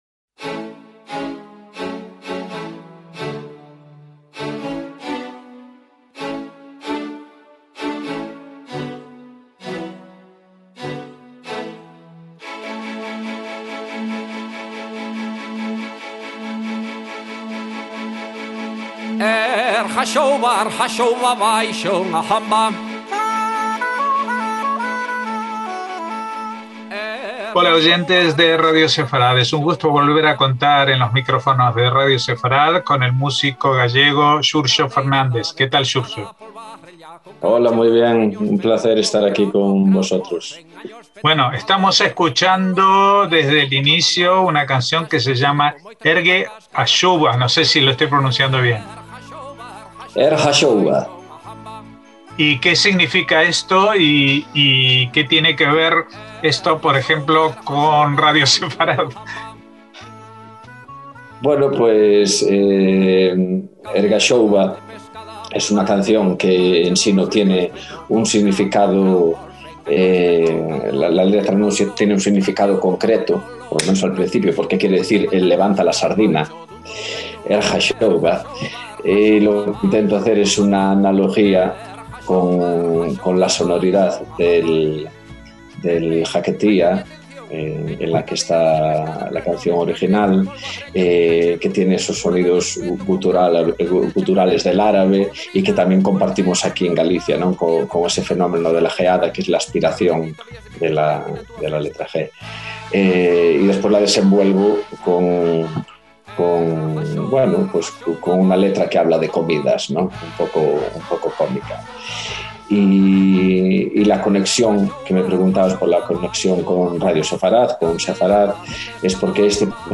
En este trabajo acerca una visión única sobre las músicas de raíz y de creación colectiva, en la que los sonidos más arcaicos de una Galicia primitiva se mezclan con el exotismo oriental.